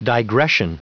Prononciation du mot digression en anglais (fichier audio)
Prononciation du mot : digression